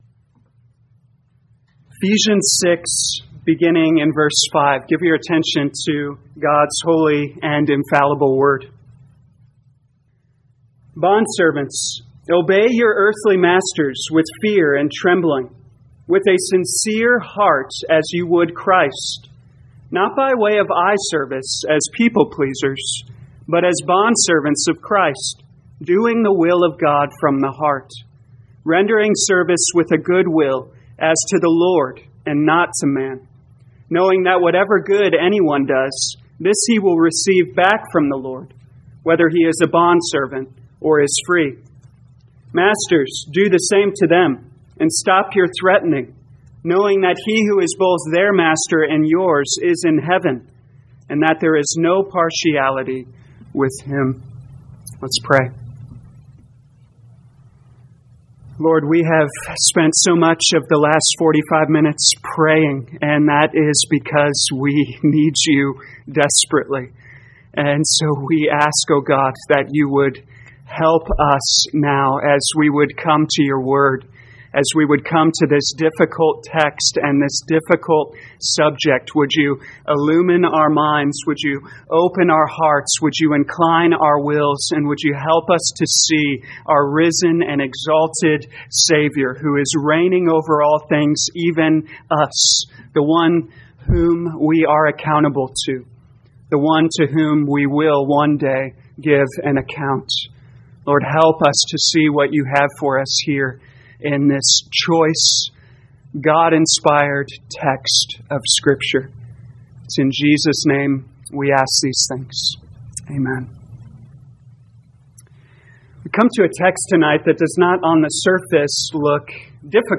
2021 Ephesians Evening Service Download